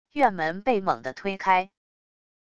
院门被猛的推开wav音频